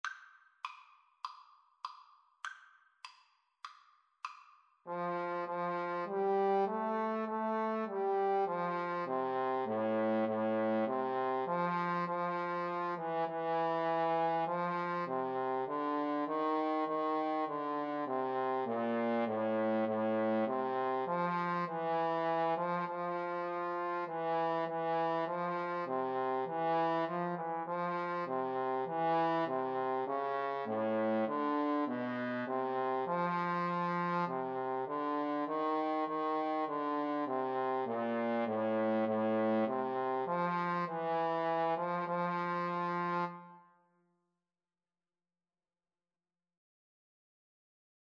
Free Sheet music for Trombone Duet
Trombone 1Trombone 2
4/4 (View more 4/4 Music)
F major (Sounding Pitch) (View more F major Music for Trombone Duet )
Classical (View more Classical Trombone Duet Music)